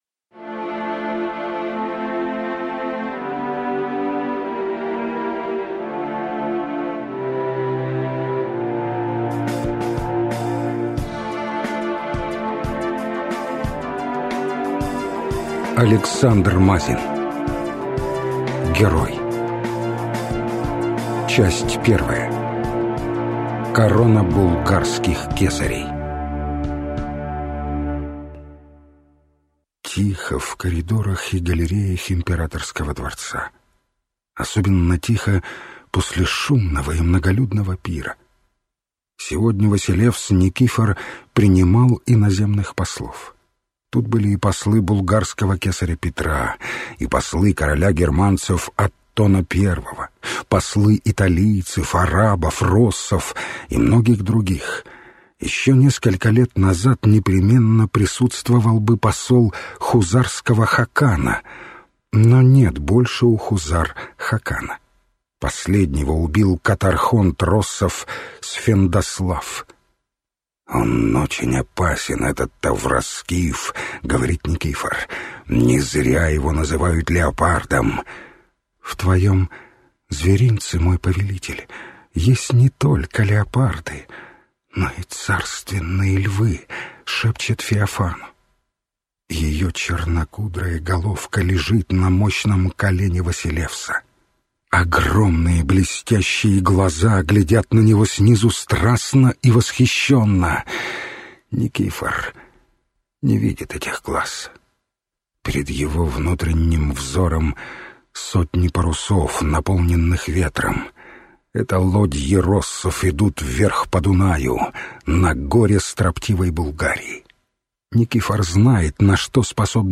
Аудиокнига Герой | Библиотека аудиокниг